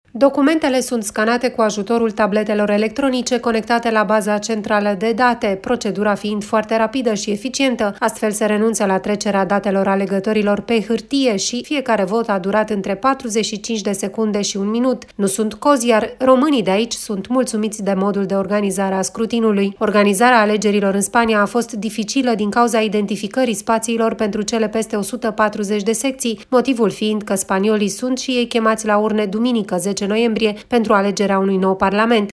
Potrivit RRA, românii din diaspora nu au avut până acum probleme şi sunt mulţumiţi de organizare. Cum s-a desfăşurat votul în Spania, ne spune de la Madrid,